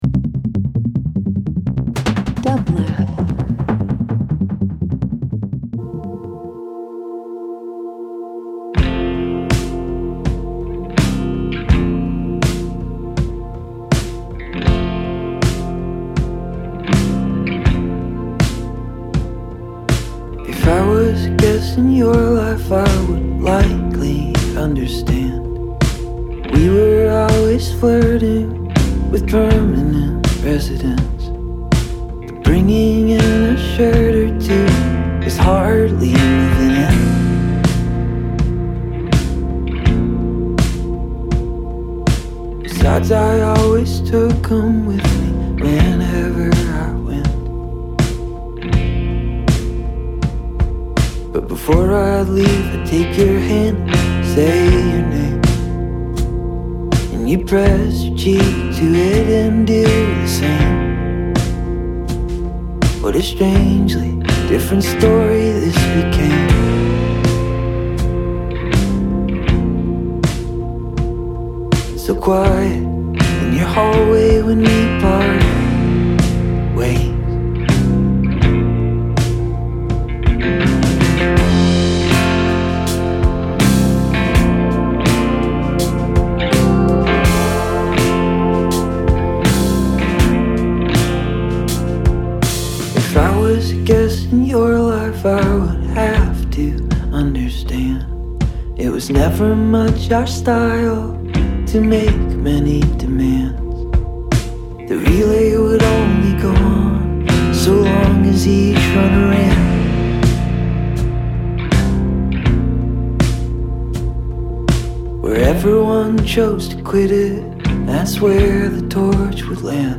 Sad music that makes me happy.
Country Indie Rock Shoegaze